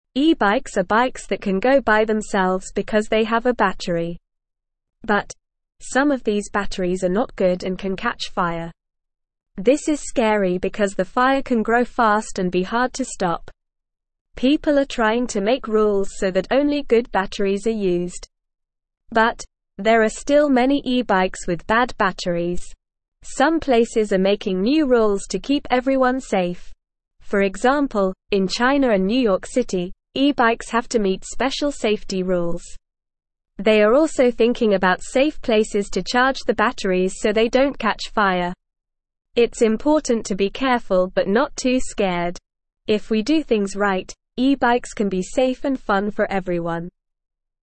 Normal
English-Newsroom-Beginner-NORMAL-Reading-E-bikes-Safe-and-Fun-with-Good-Batteries.mp3